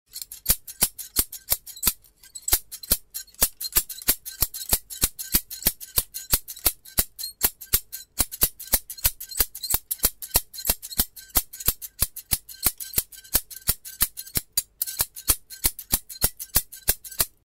Подборка включает четкие и реалистичные записи, которые подойдут для видео, подкастов или звукового оформления.
Звук старых ножниц